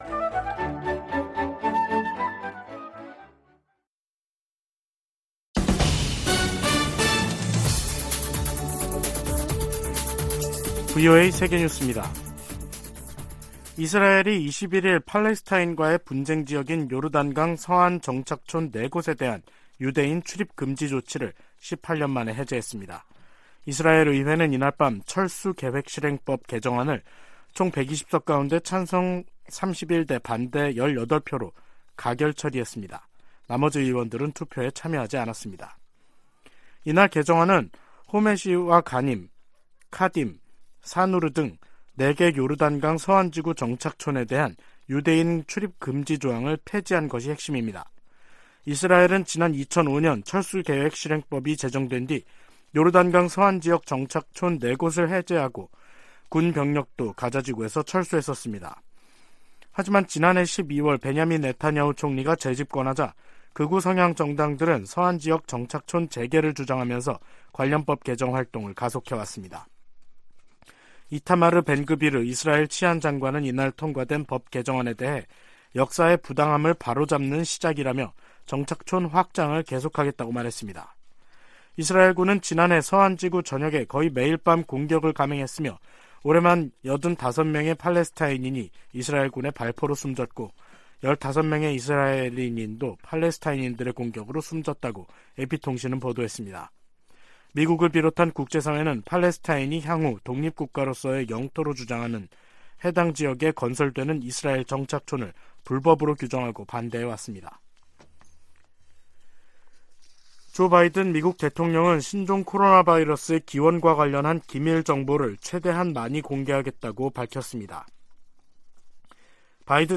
VOA 한국어 간판 뉴스 프로그램 '뉴스 투데이', 2023년 3월 21일 3부 방송입니다. 북한이 모의 핵탄두를 탑재한 미사일 공중폭발 시험훈련에 성공했다고 밝히면서 전술핵 위협이 한층 현실화했다는 평가가 나옵니다. 유엔 안전보장이사회가 북한의 대륙간탄도미사일(ICBM) 발사에 대응한 공개회의를 개최하고 북한을 규탄했습니다. 북한에서 살인과 고문, 인신매매 등 광범위한 인권 유린 행위가 여전히 자행되고 있다고 미 국무부가 밝혔습니다.